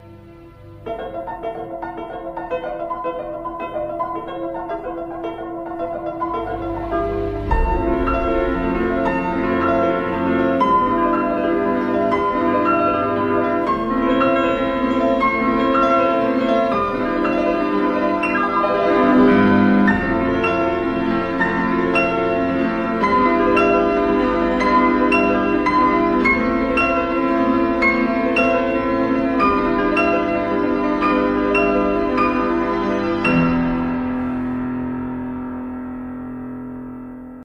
I love your account so much your voice is so peaceful